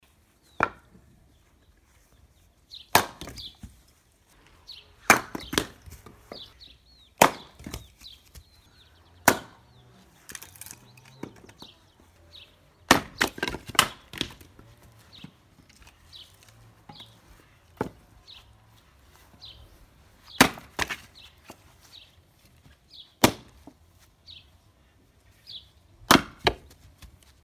Звуки топора
На этой странице собраны разнообразные звуки, связанные с работой топора: от мощных ударов по дереву до звонкого отскока лезвия.
Звук рубки дров в деревне